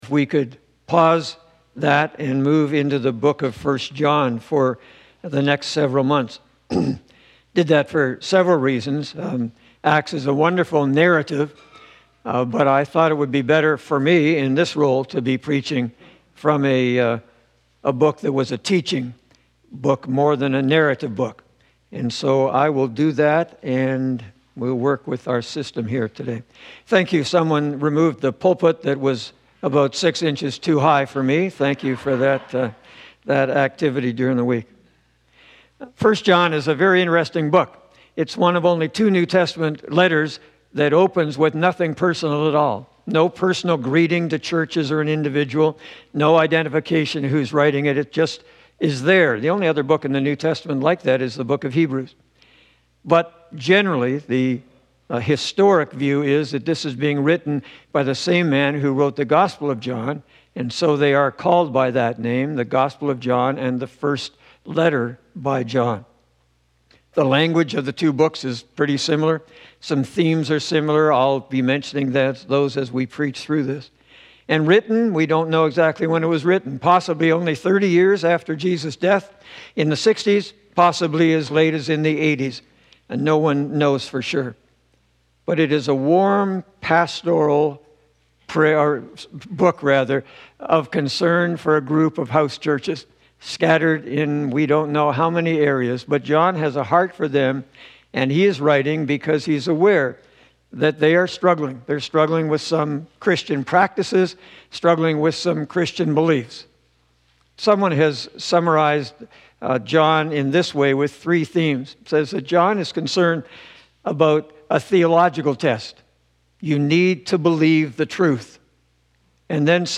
The first sermon in a series on 1 John.